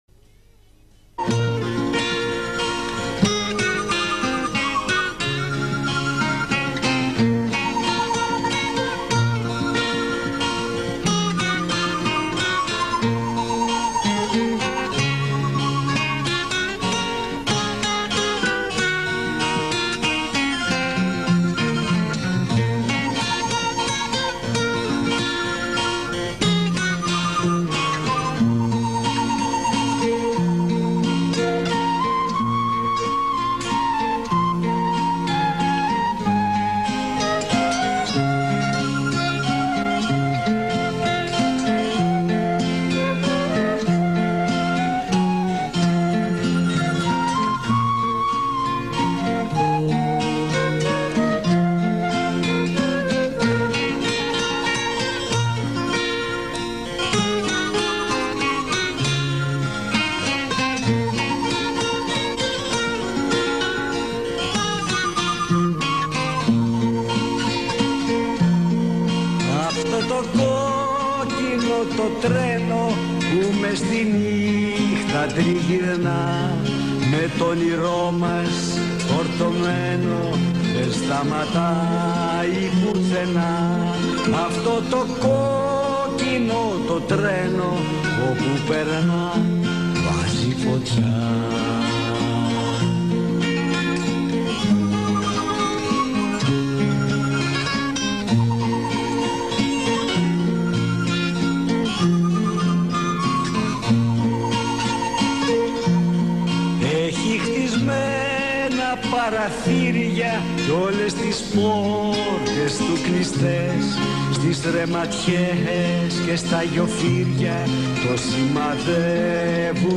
Песня-увертюра к фильму